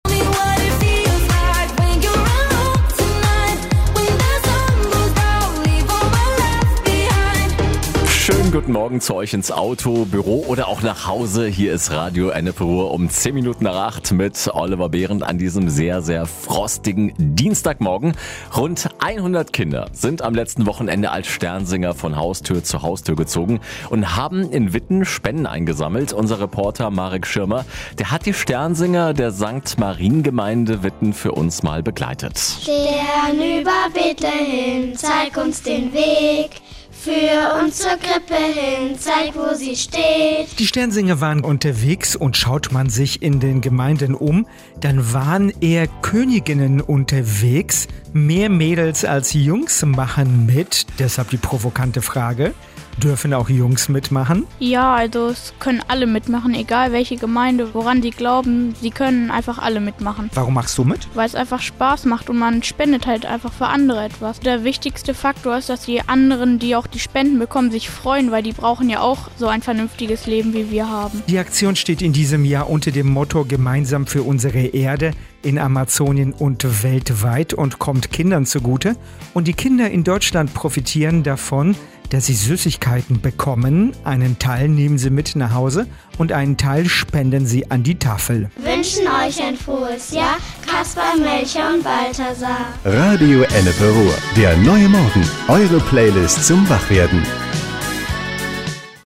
Radio Ennepe Ruhr hat die Kinder in der Gemeinde Sankt Marien begleitet.